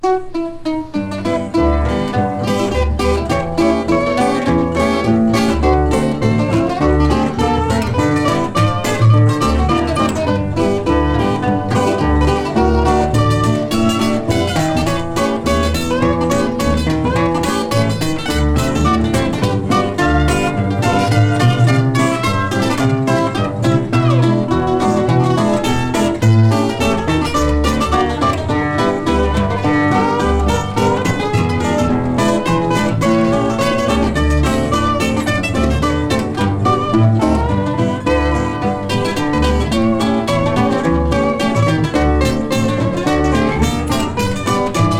Jazz　USA　12inchレコード　33rpm　Stereo